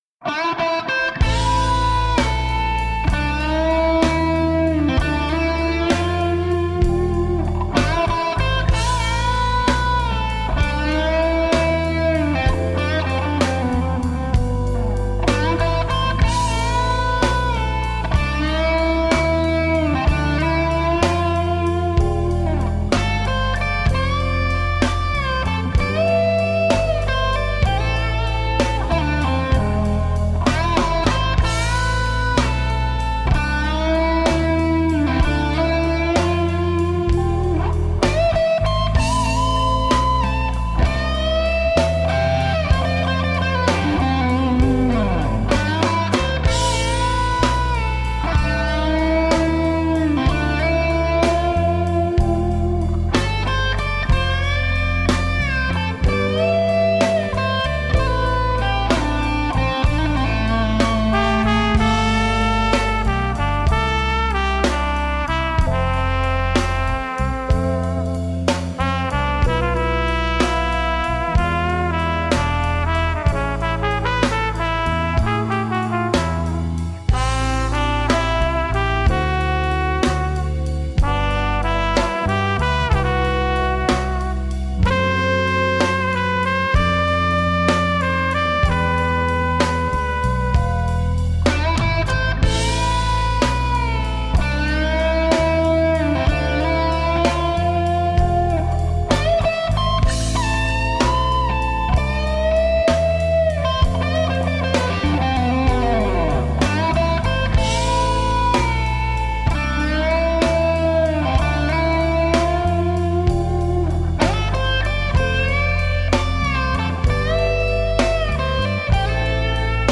Guitar-driven, groove-oriented feel-good jazz/r&b tunes.
Tagged as: Jazz, Other